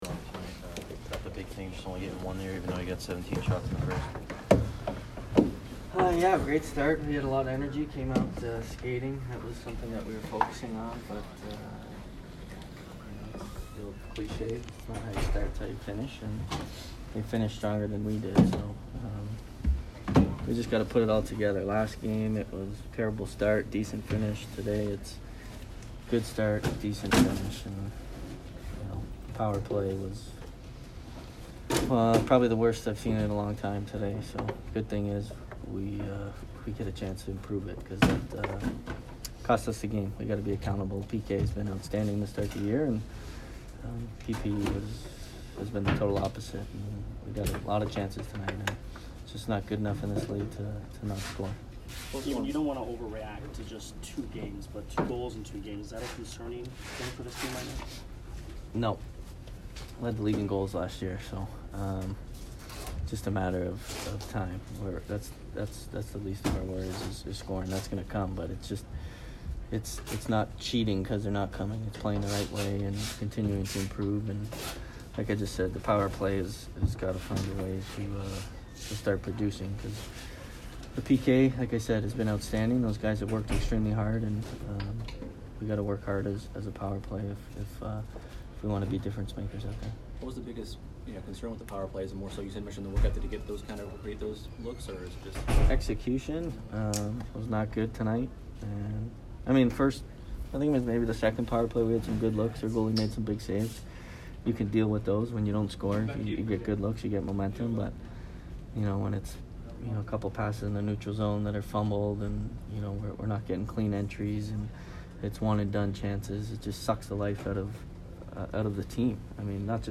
Steven Stamkos post-game 10/11